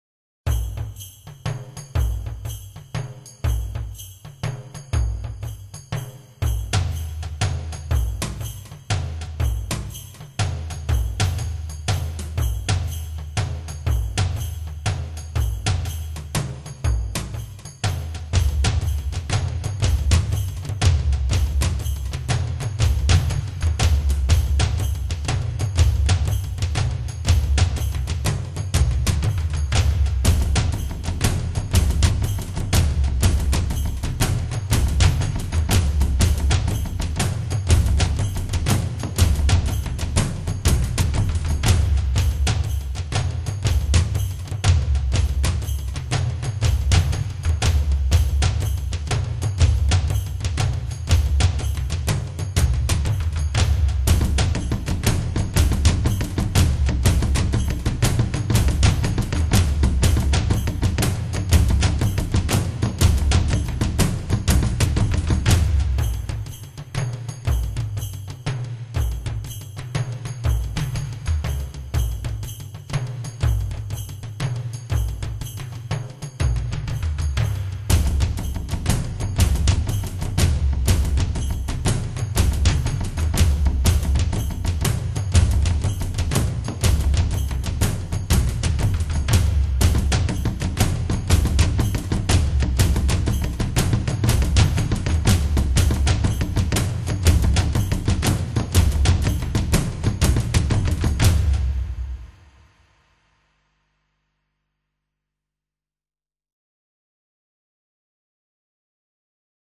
HIFI纯音乐发烧碟
很有节奏感．